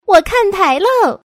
woman
kanpai.mp3